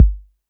808-Kicks03.wav